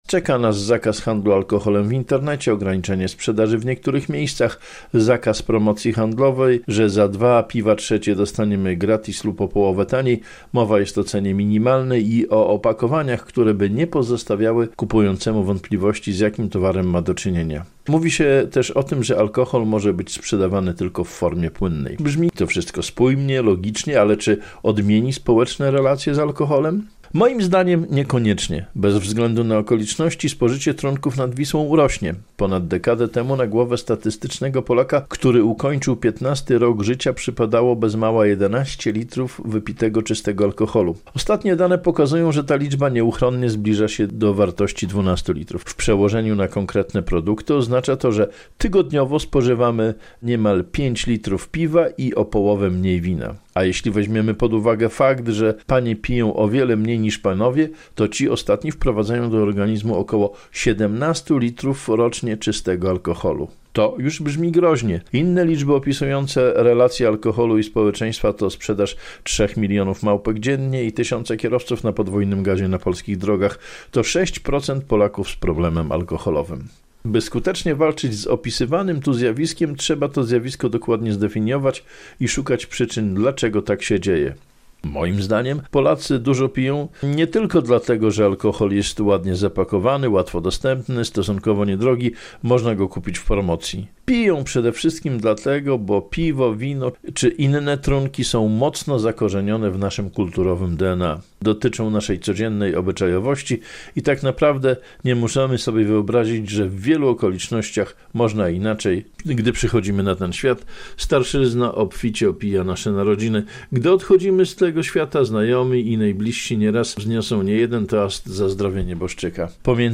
Czy będziemy spożywać mniej alkoholu - felieton